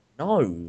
Slightly agitated no